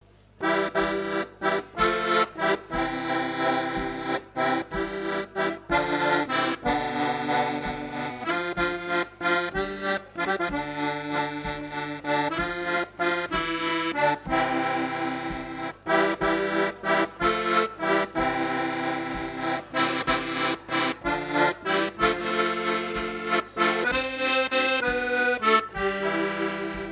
danse : valse
Fête de l'accordéon
Pièce musicale inédite